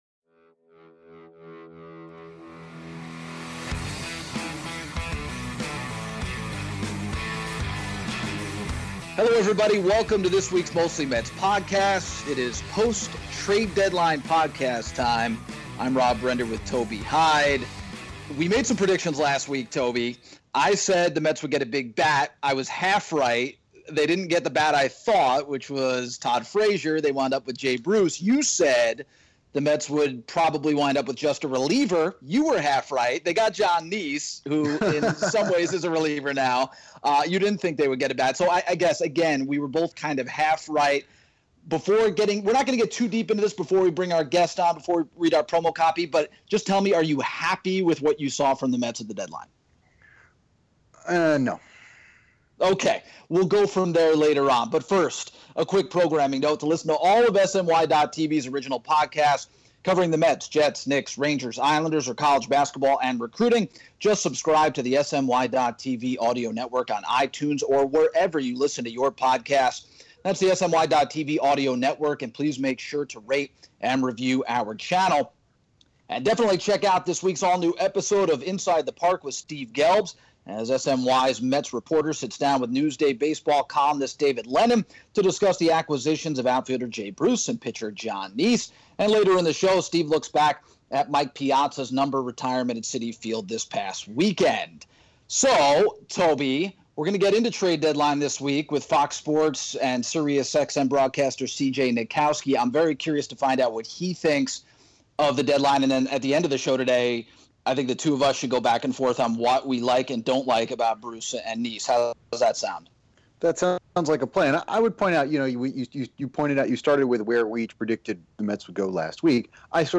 Mostly Mets: CJ Nitkowski calls in